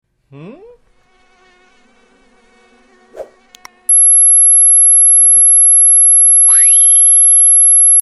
Pest repellent circuit | Mosquito sound effects free download